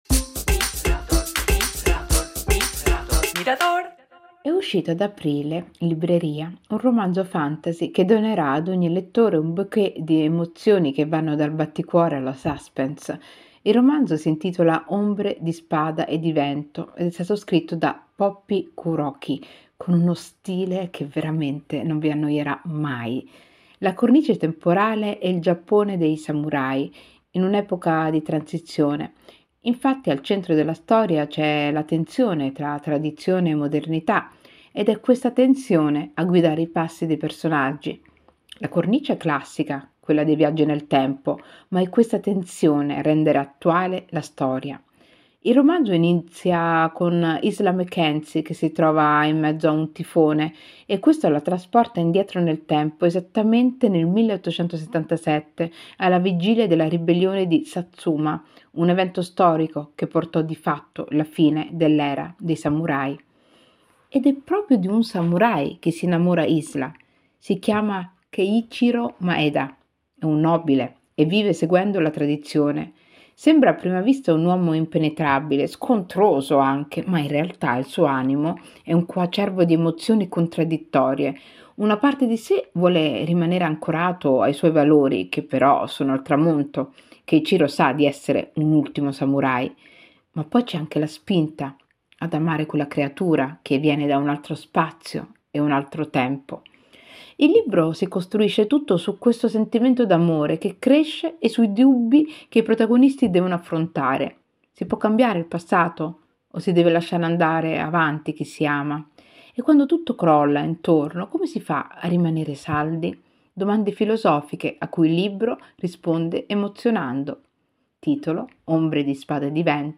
Libro recensito da Igiaba Scego